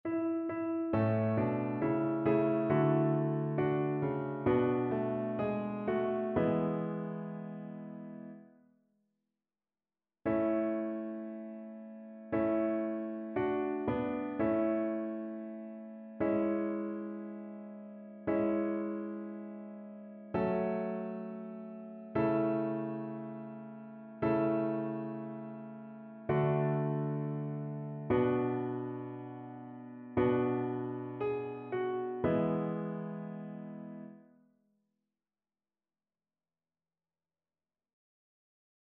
Chœur
annee-abc-temps-du-careme-messe-christmale-psaume-88-satb.mp3